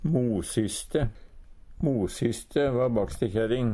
mosyste - Numedalsmål (en-US)